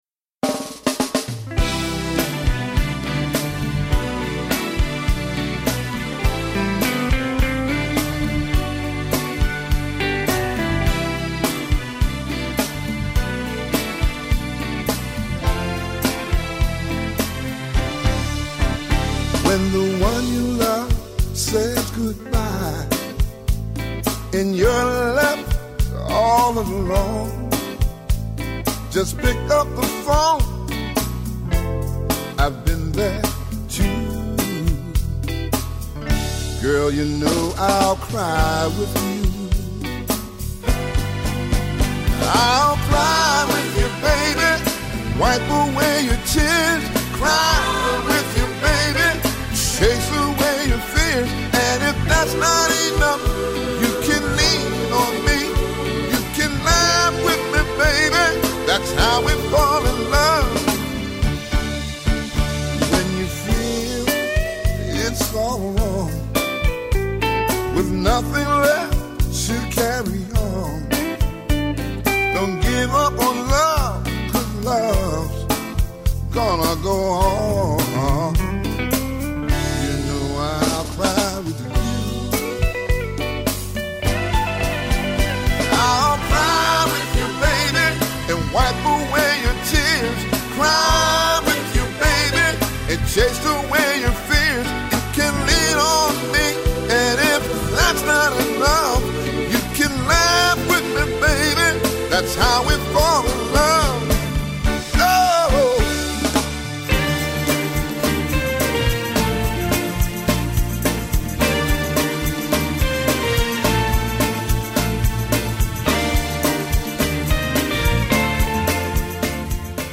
"Old School" '70s R&B/Soul songs
'70s R&B/Soul